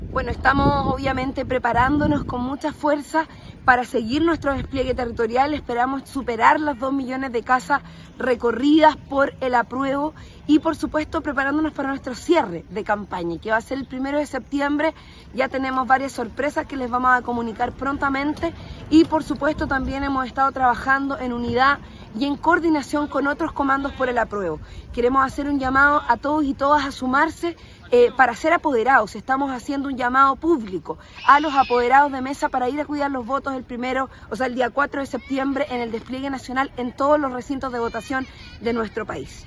Karol Cariola, co-coordinadora del Comando de Aprueba x Chile, hizo una invitación a sumarse al gran despliegue de actividades por el Apruebo que se estarán realizando durante el fin de semana y, además, agregó que